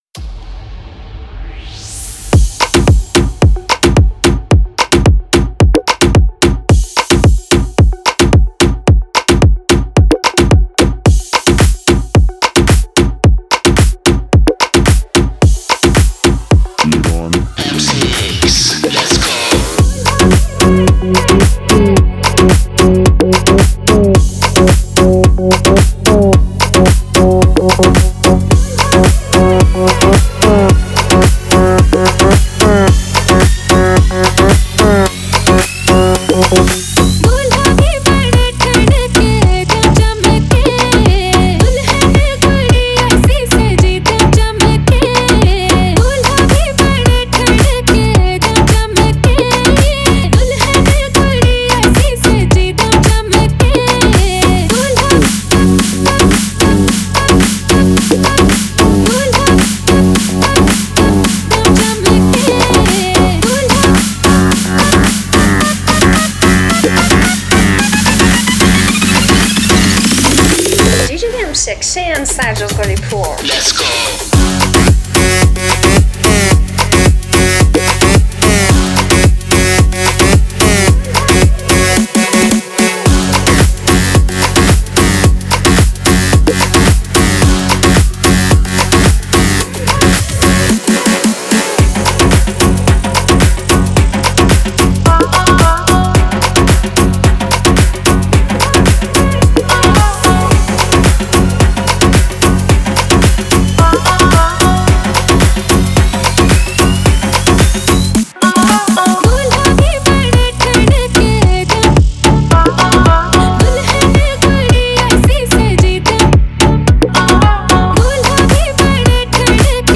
Download the new Hindi remix for the club